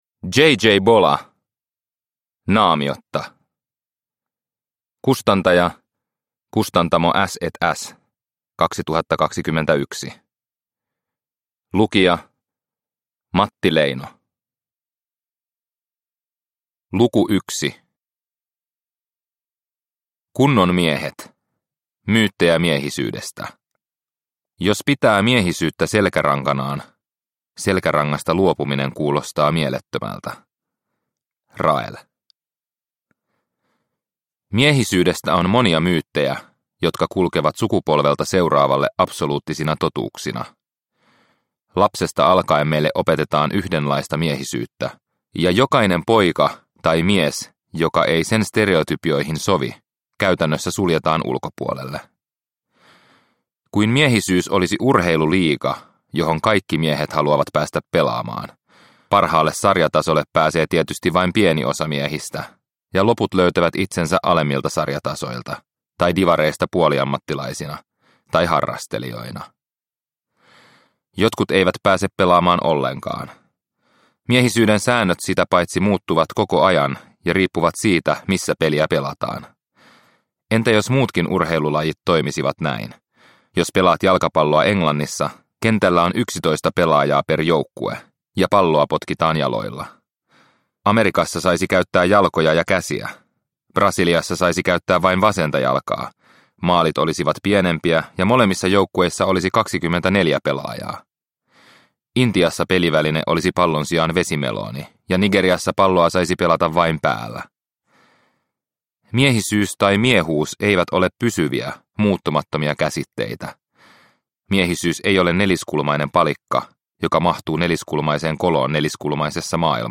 Naamiotta – Ljudbok – Laddas ner